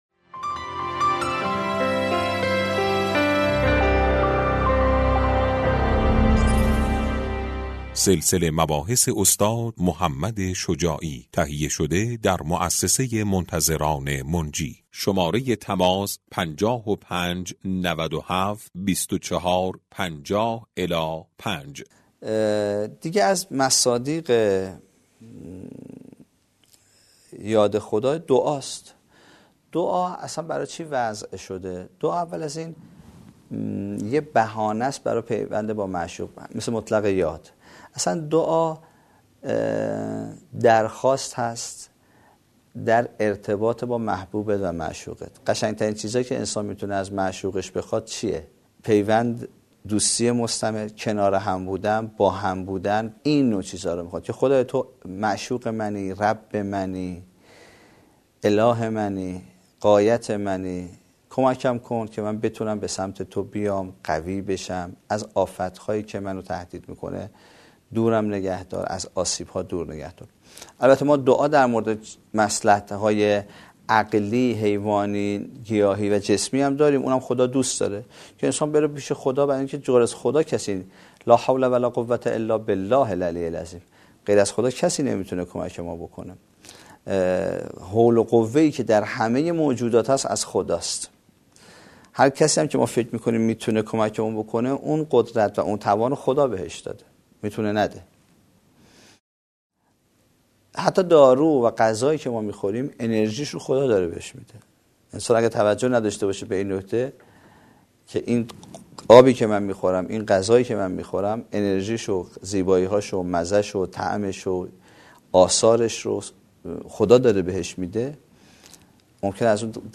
سلسه مباحث